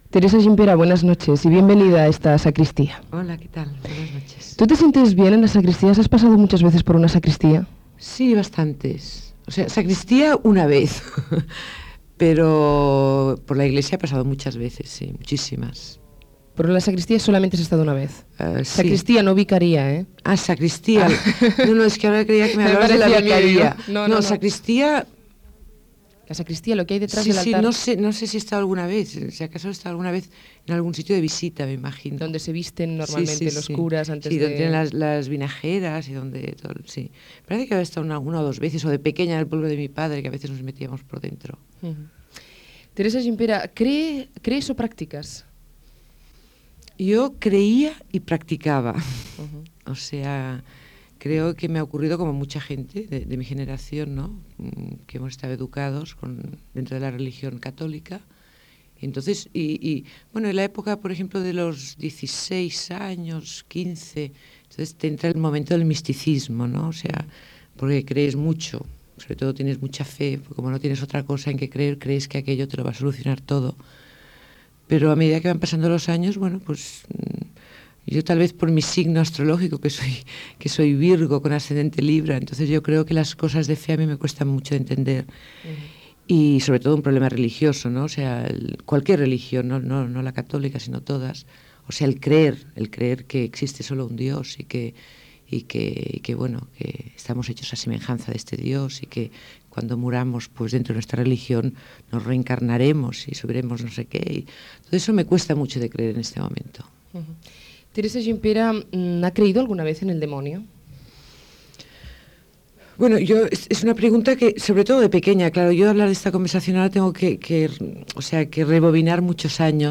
Secció "La sacristía", amb una entrevista a la model i actriu Teresa Gimpera sobre les seves creences religioses i la seva opinió de l'església catòlica i d'altres religions